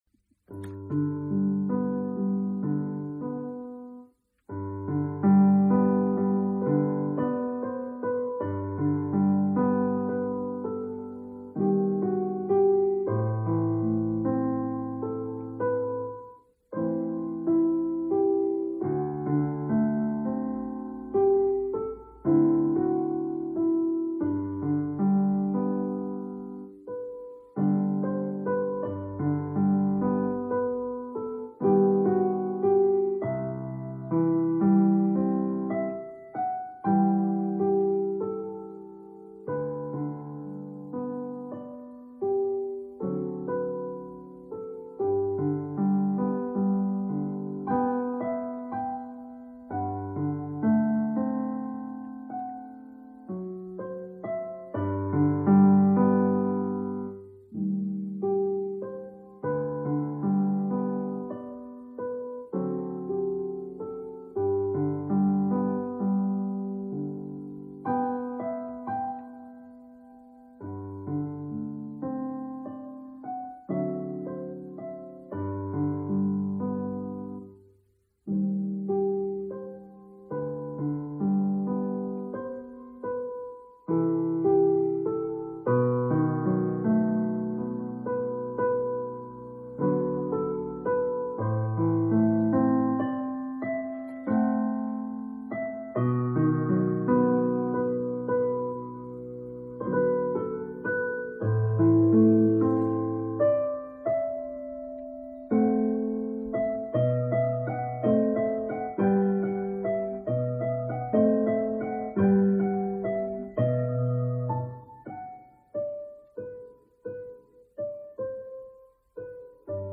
(Megjegyzés: A digitális  zongorám rögzítette az előadásom, amit a telefonommal àtvettem, ezért esetleges külső zajok is észlelhetők. Sajos nem tudok megszabadulni az erős lámpalázamtól, ami miatt mindig hibázom az előadásomban... / Nota:  Il pianoforte digitale ha registrato la mia esecuzione e l'ho registrata/ripresa con il telefonino, perciò  eventuali rumori esterni possono essere percepiti. Purtroppo non riesco a liberarmi della forte ansia da riflettori/palcoscenico/registrazione, perciò sempre sbaglio durante l'esecuzione ....)